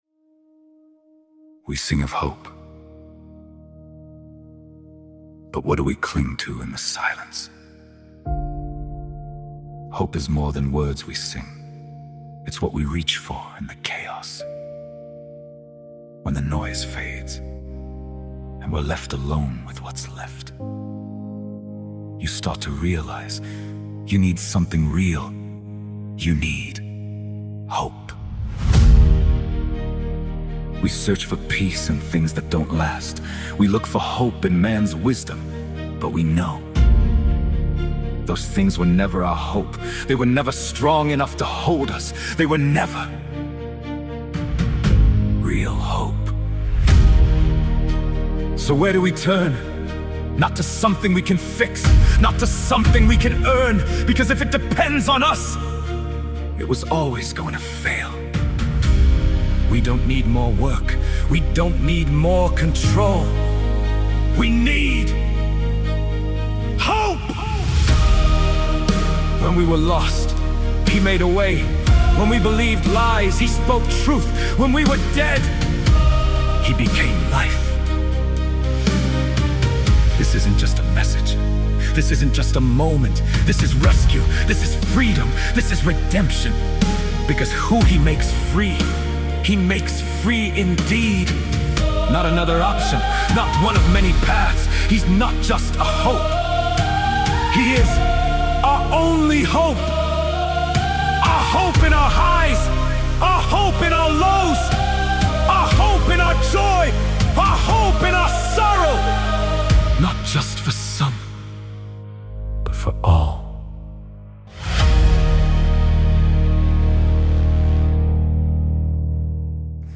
Your Only Hope – Part 4 | Sermons
Sunrise Resurrection Service The First Coming Of The Lord Jesus Christ To His Chosen People And His Chosen Place.